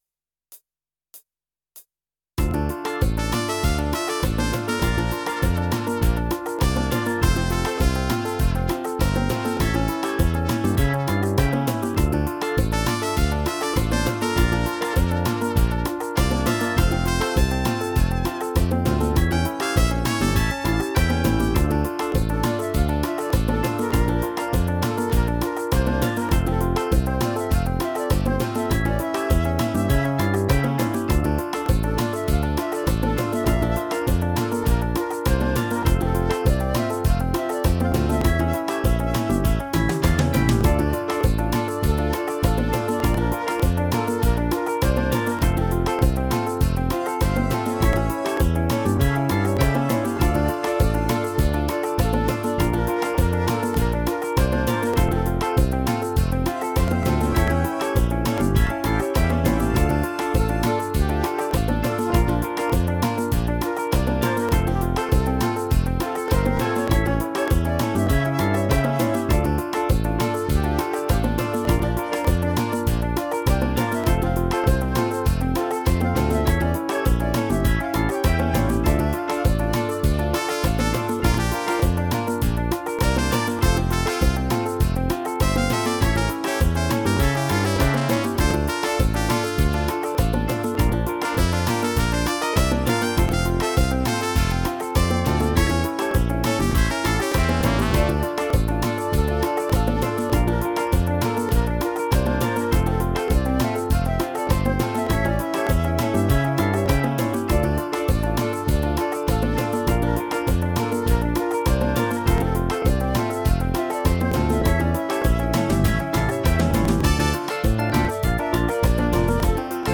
mehrspurige Instrumentalversion